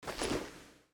equip_leather5.ogg